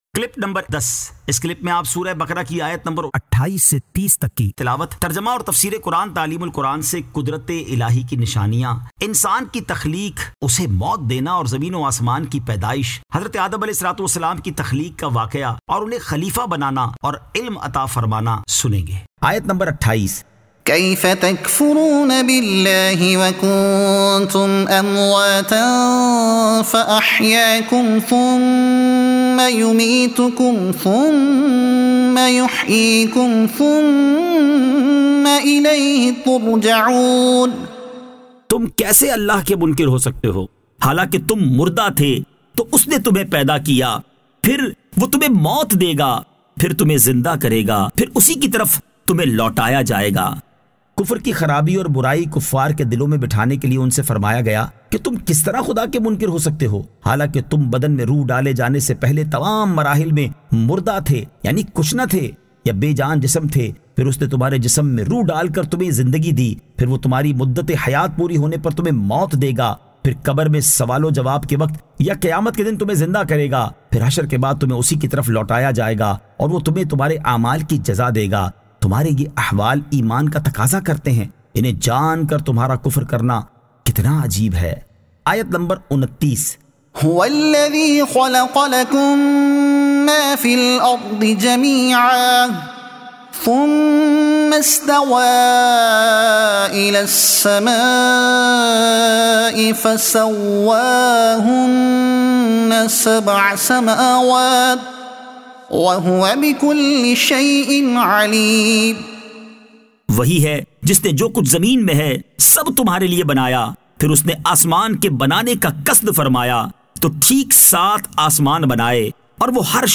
Surah Al-Baqara Ayat 28 To 30 Tilawat , Tarjuma , Tafseer e Taleem ul Quran